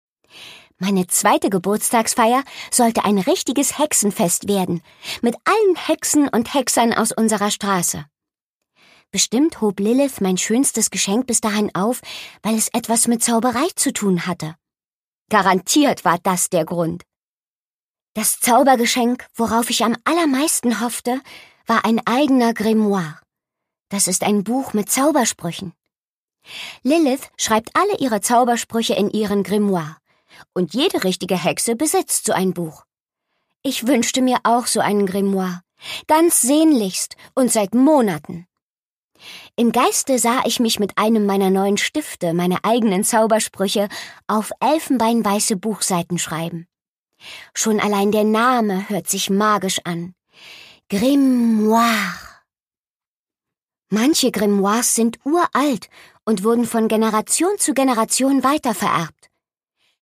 Produkttyp: Hörbuch-Download
Fassung: Ungekürzte Lesung